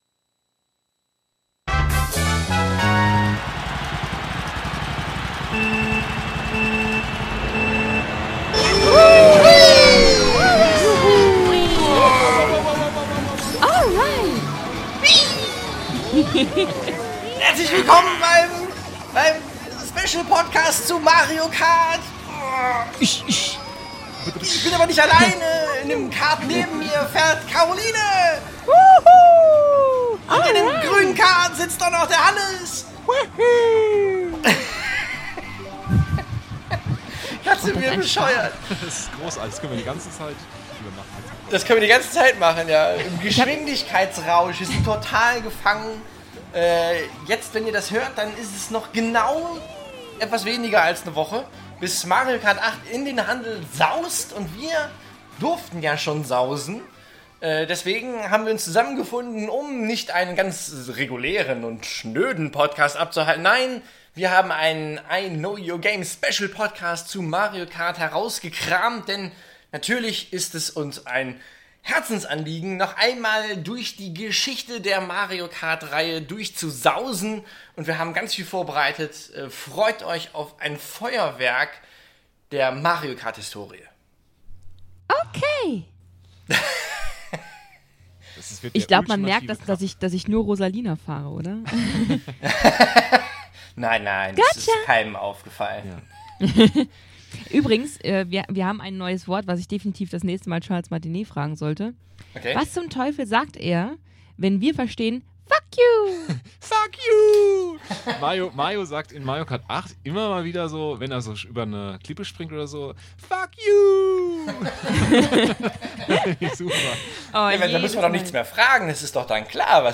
Um den Release von Mario Kart 8 gebührend zu feiern, haben wir uns drei Stunden über die komplette Mario Kart-Reihe unterhalten.